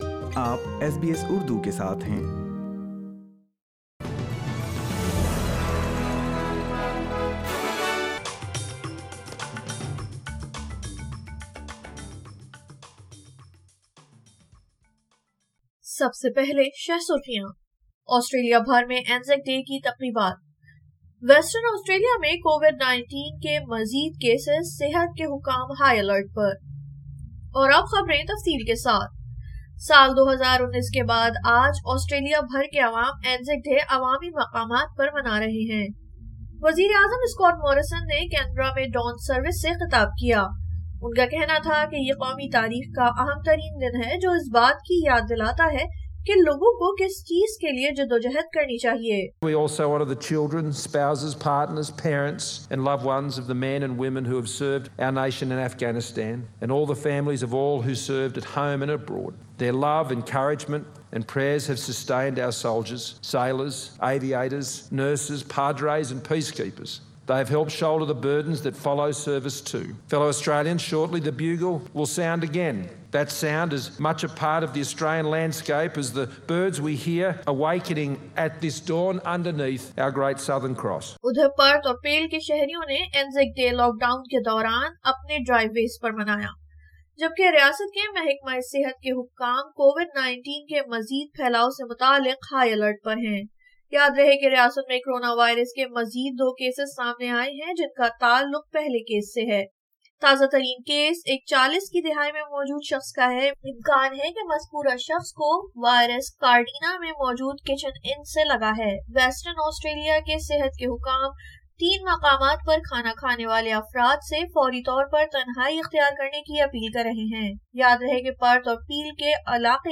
Urdu News 25 April 2021